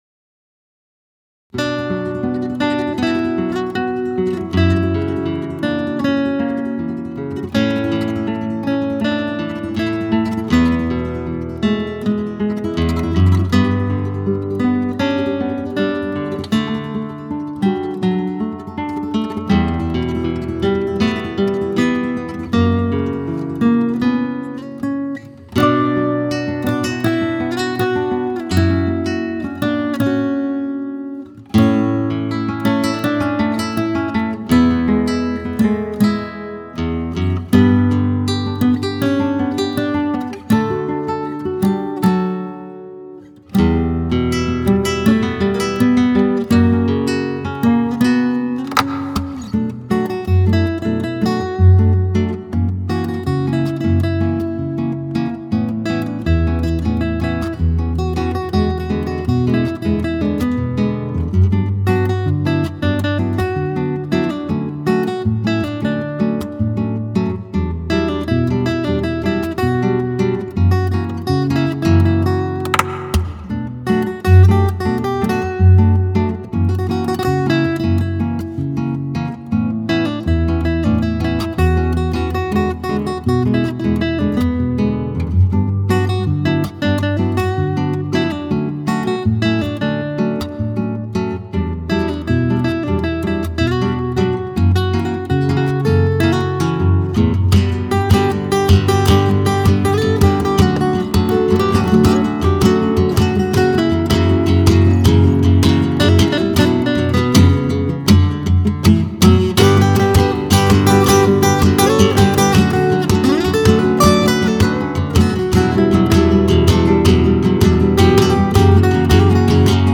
an acoustic and nylon string duo